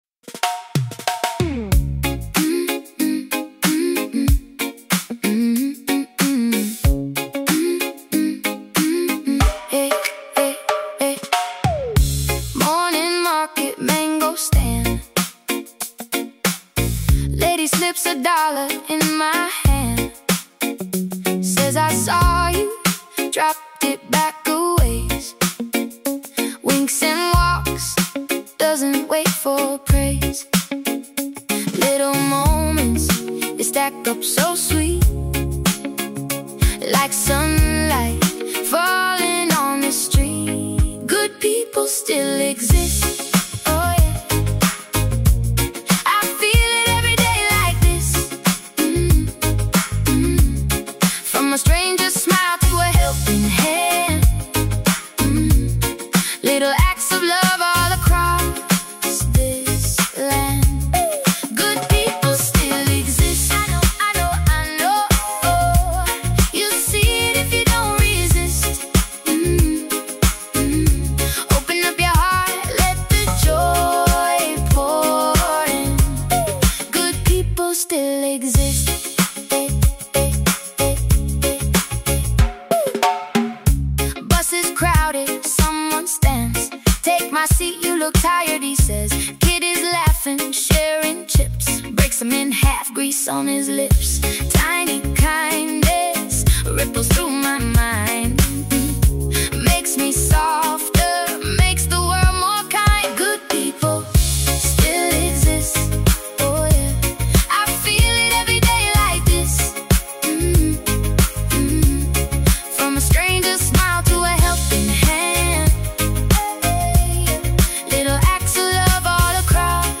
As it plays, the tone becomes more comforting.